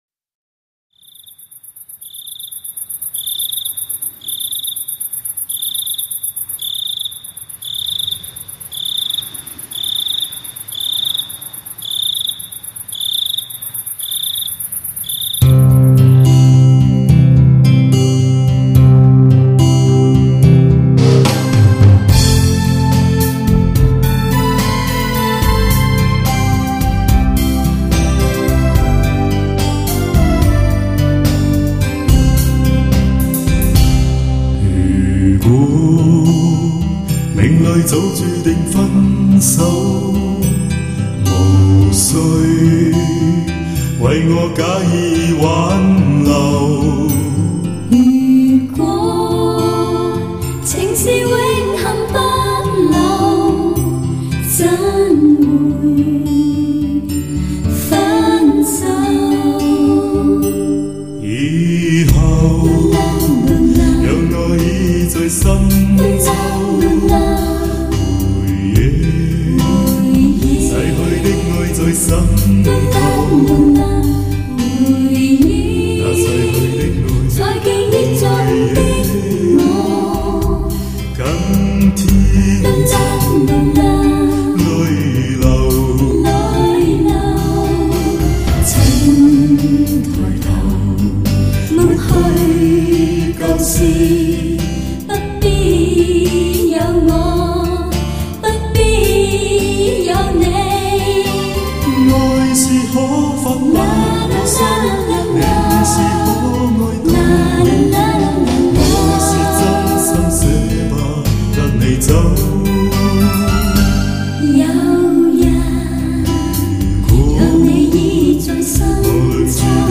★ 粤语情歌经典，带来越听越入心的亲切感，如诗如画★